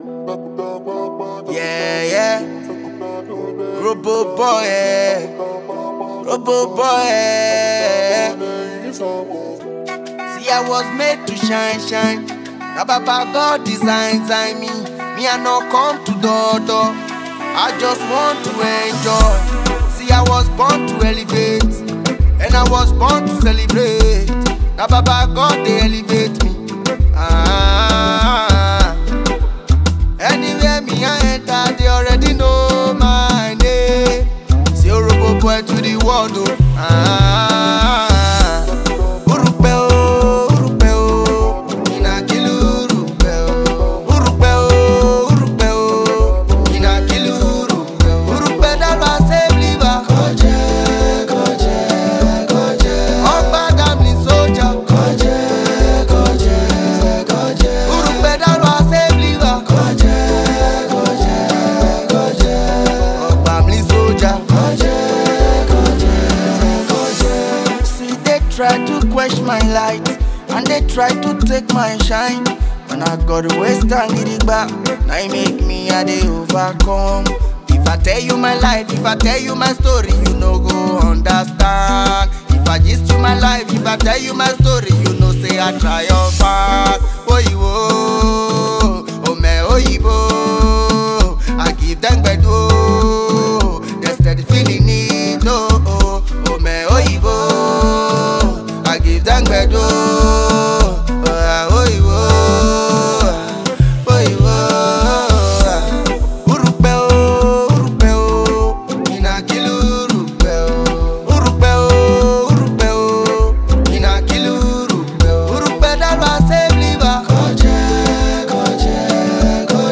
Nigerian Afro Pop/ AfroBeat artiste